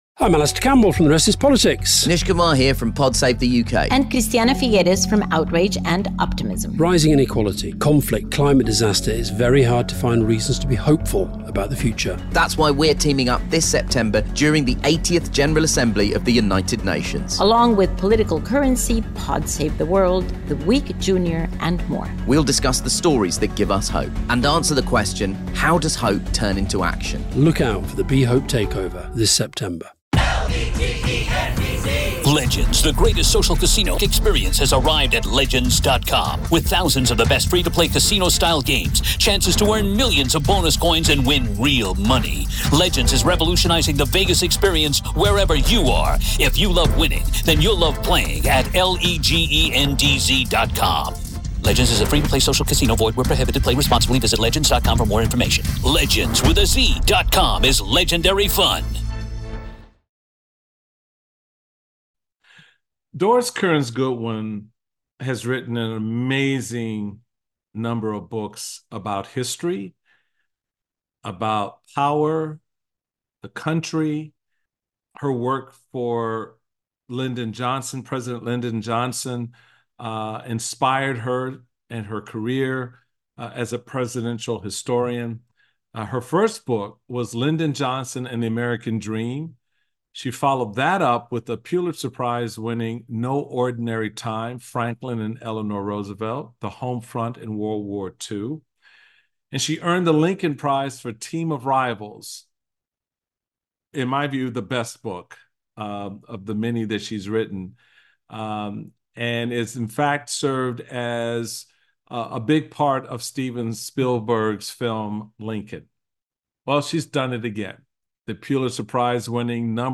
Michael Steele speaks with Pulitzer Prize-winning presidential historian, Doris Kearns Goodwin about past and present challenges to democracy.